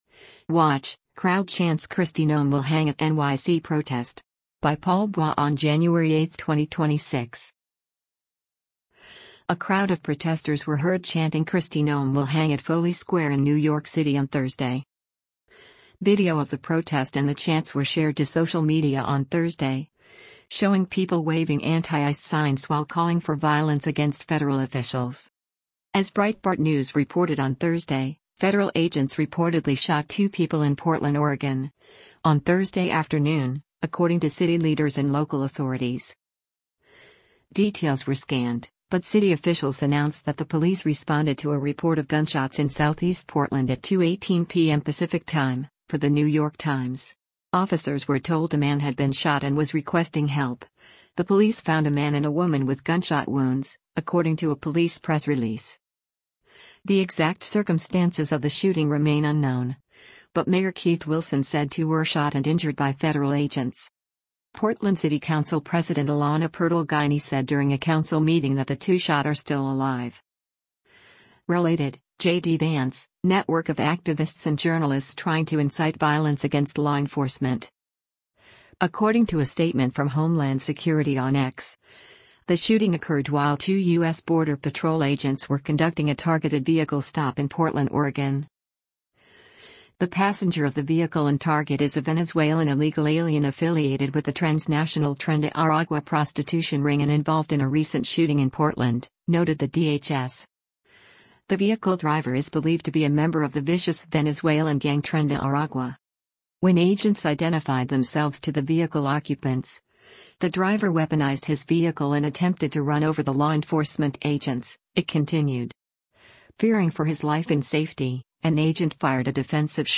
A crowd of protesters were heard chanting “Kristi Noem will hang” at Foley Square in New York City on Thursday.